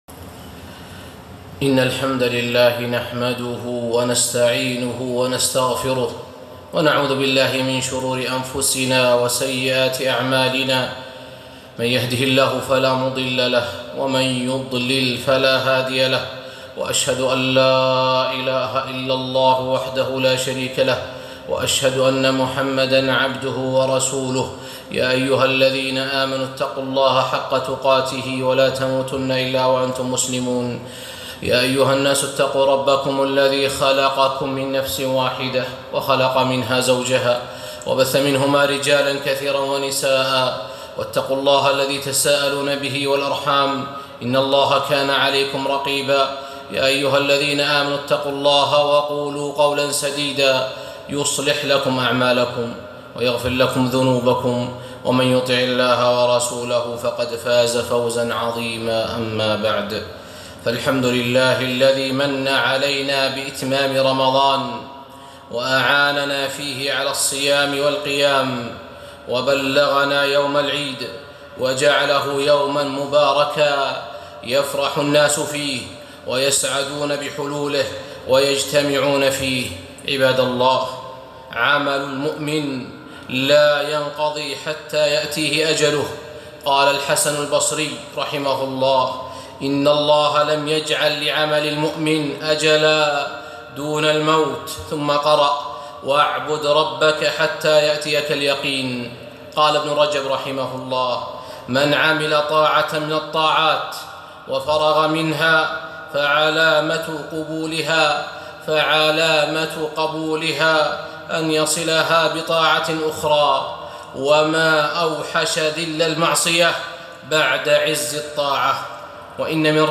خطبة - إتباع الطاعات بالطاعات